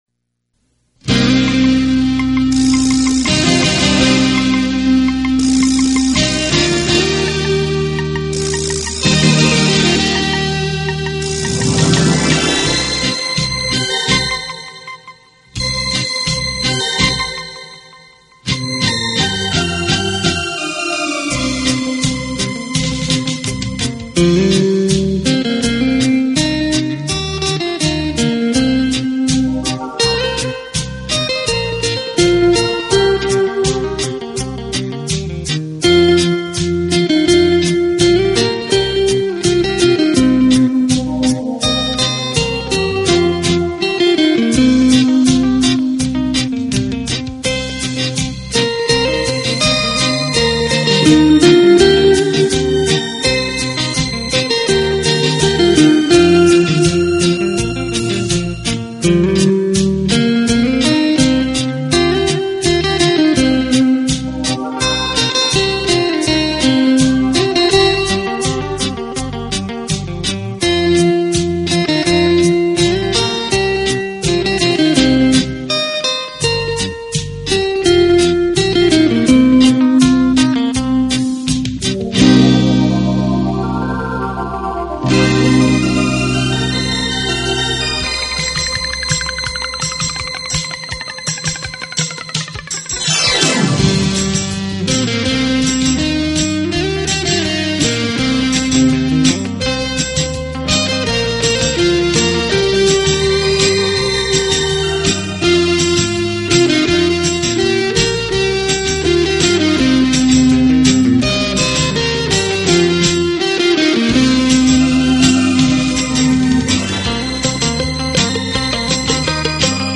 专辑音色清脆动人且温馨旖丽，不禁展示了精彩绝伦的空间感，而且带出吉他
音箱共鸣声的无限通透。就如在您身边演奏一般，自由、惬意、浪漫……各类
用吉他的清脆表现大师音乐的干净、深度和静谧美丽得让人心碎的旋律。